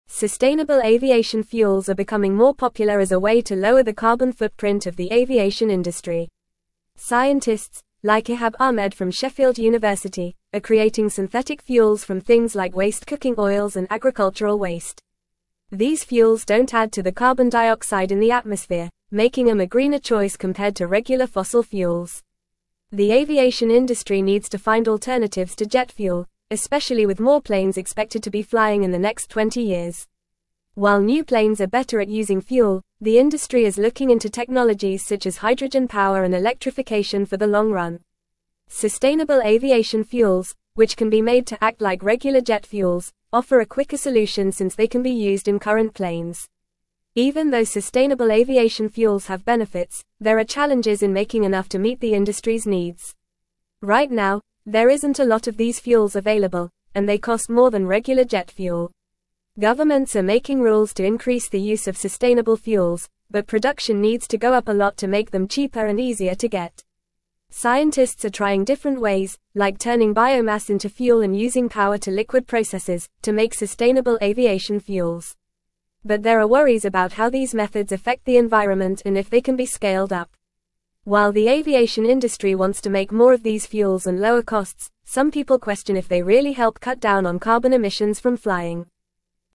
English-Newsroom-Upper-Intermediate-FAST-Reading-Advancements-in-Sustainable-Aviation-Fuels-for-Greener-Future.mp3